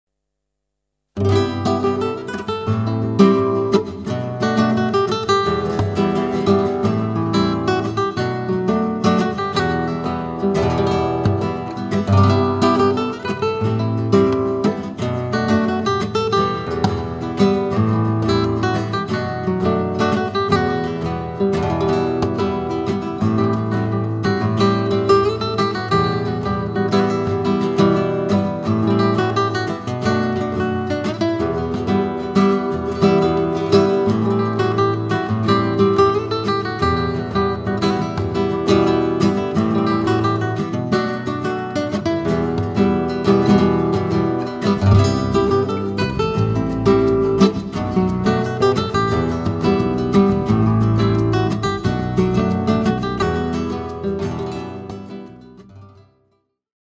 rumba